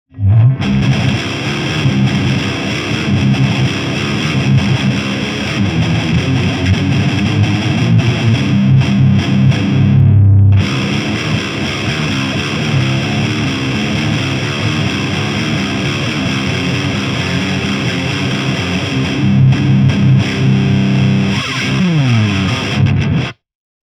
GROOVE TUBE GT-ECC83Sは低音が出るのとコンプ感が特徴です。
ゲインアップはしますが、ハイが多少出なくなります。
MTRはZOOM MRS-8を使いました。マイクはSM57とPG57
GAIN7 Bass8 Middle8 Treble7
LEED2 BEHRINGER PREAMP BOOSTER